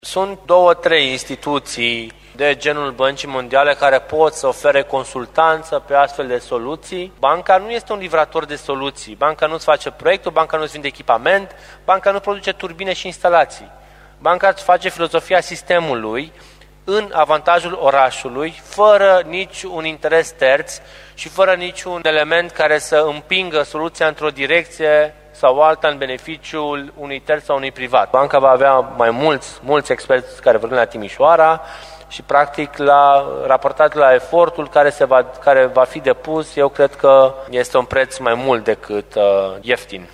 În cadrul ședinței, viceprimarul Ruben Lațcău a explicat de ce a fost aleasă Banca Mondială și ce va face instituția pentru cei 900.000 de lei plătiți de primărie: